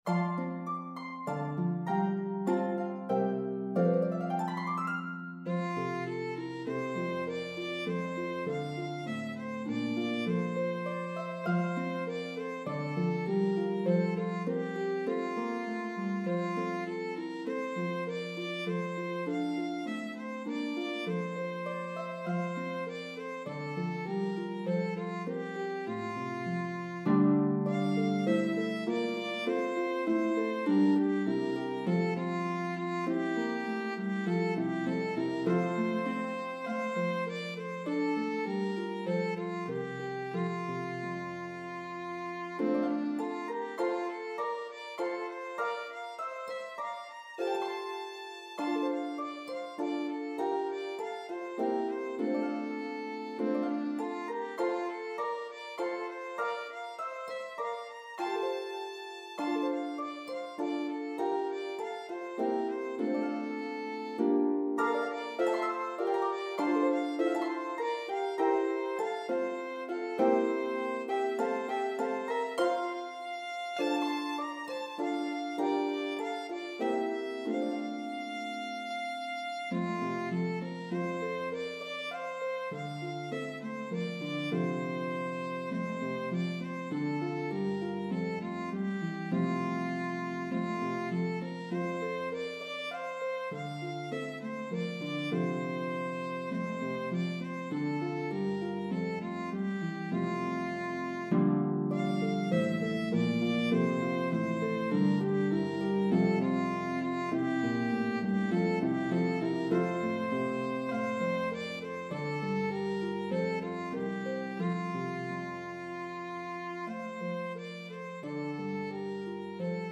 The exuberant French Carol